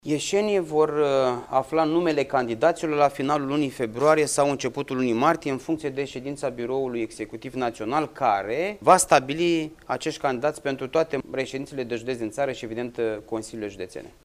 Soluţia alegerilor anticipate este singura variantă agreată de PNL, a susţinut, astăzi,  într-o conferinţă de presă, la Iaşi, ministrul mediului, Costel Alexe.